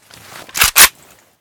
ak12_misfire.ogg